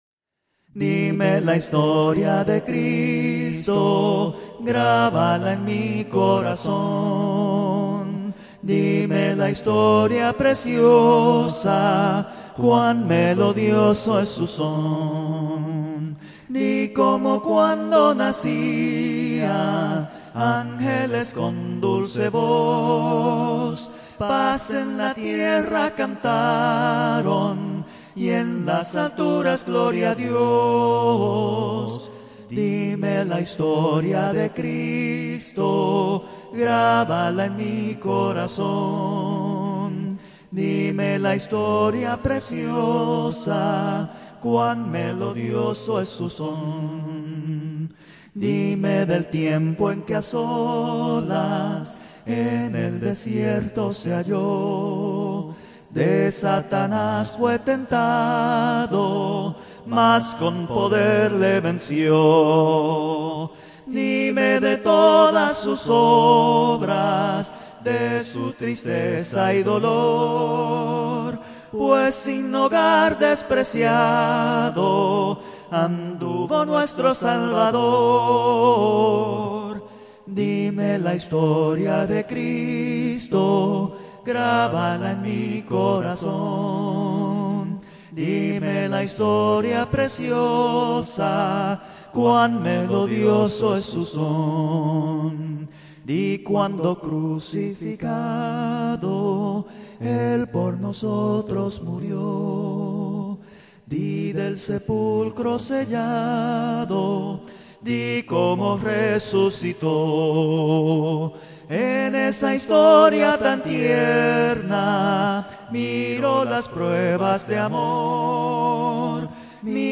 Cánticos Cristianos A Cappella, Gratis
Con Múltiples Voces: